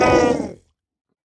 sounds / mob / sniffer / hurt3.ogg
hurt3.ogg